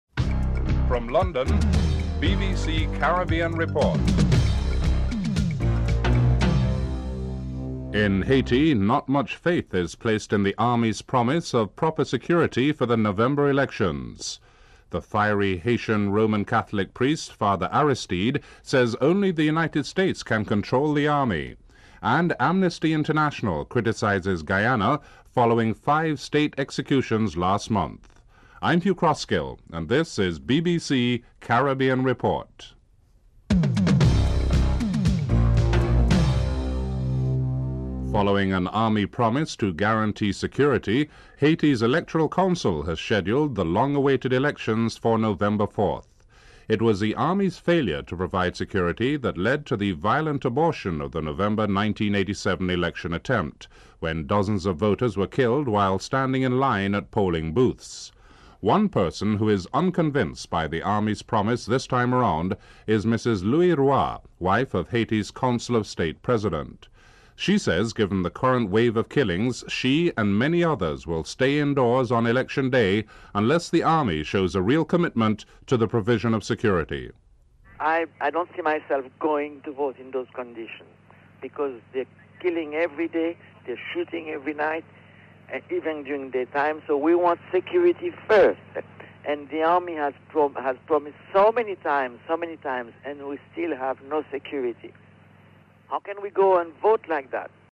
The British Broadcasting Corporation
1. Headlines (00:00-00:37)